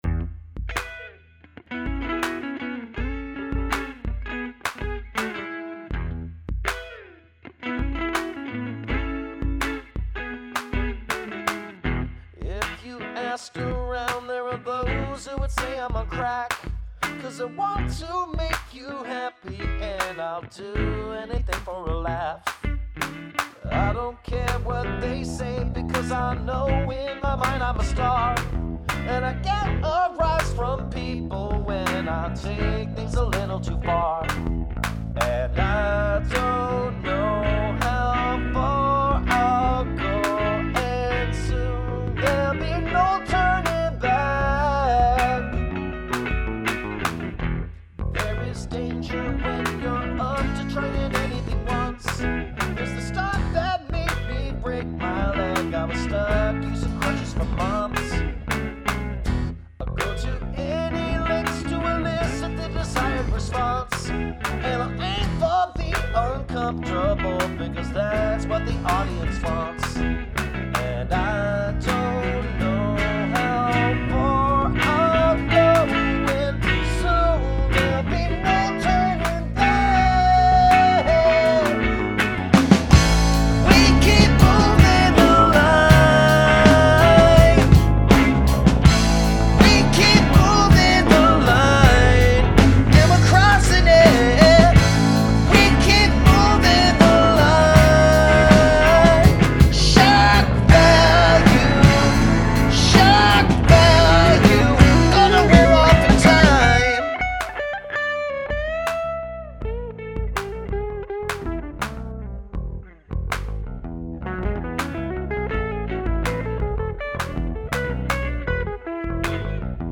Great solo!